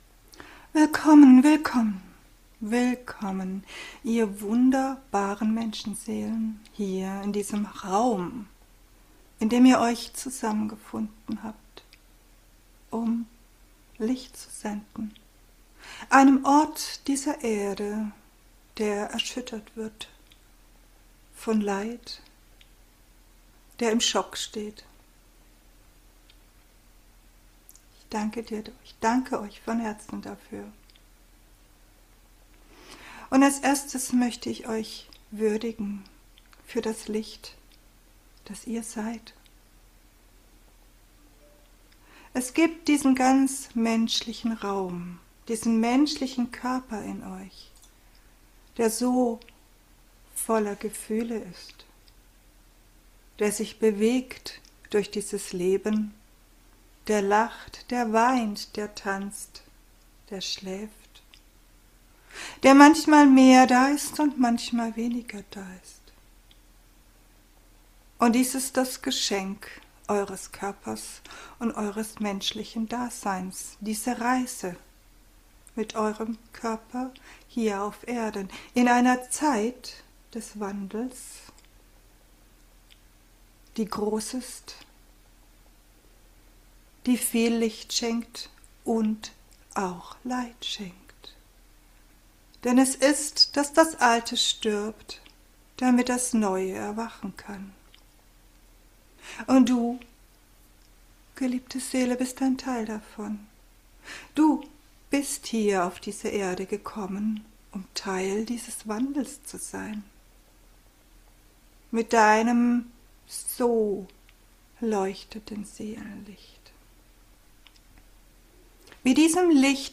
Geführte Meditation
Der Call beinhaltet neben einer wertvollen Einführung eine hochschwingende gechannelte Meditation, welche du auch separat als Audio downloaden kannst.